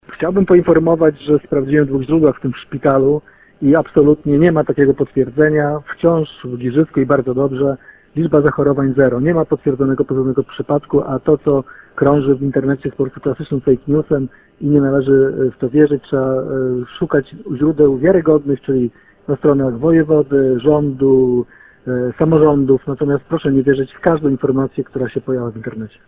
„Nie ma koronawirusa w Giżycku!” – oświadcza burmistrz
– Nie ma koronawirusa w Giżycku. To fake news! – mówi Wojciech Karol Iwaszkiewicz, burmistrz miasta.